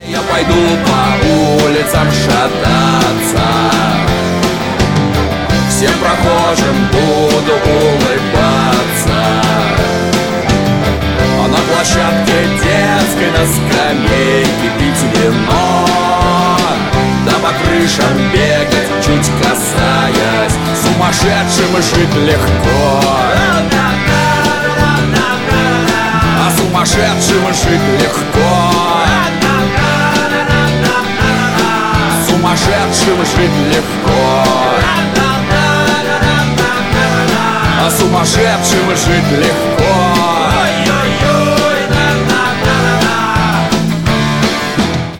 позитивные
русский рок
панк-рок